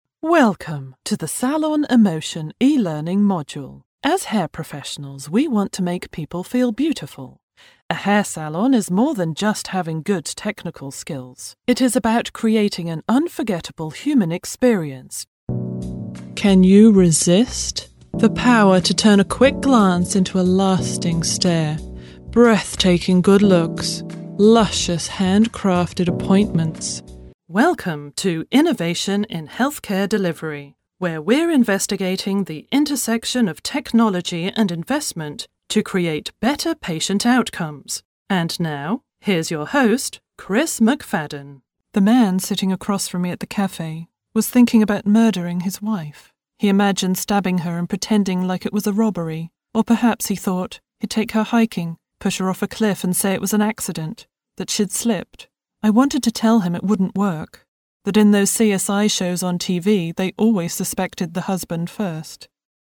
English (British)
Sophisticated
Warm
Confident